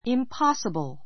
impossible A2 impɑ́səbl イン パ スィ ブる 形容詞 不可能な; ありえない 反対語 possible （可能な） an impossible task an impossible task 実行不可能な仕事 It is impossible for her to do this work in a day or two.